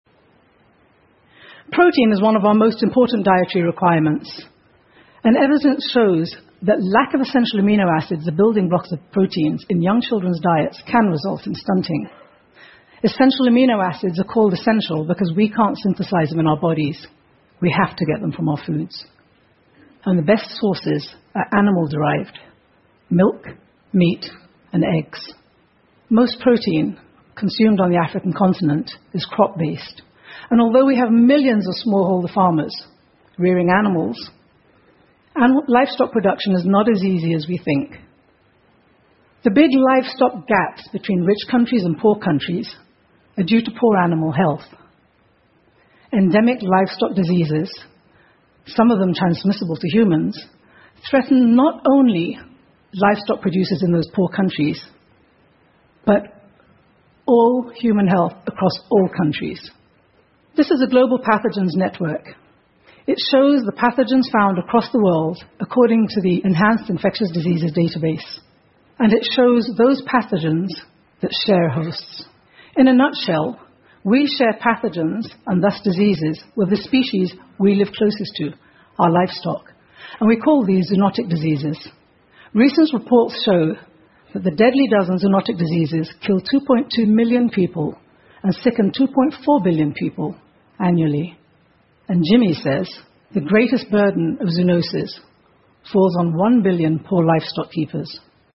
TED演讲:我们是如何通过手机短信帮助饥饿的孩子填饱肚子的() 听力文件下载—在线英语听力室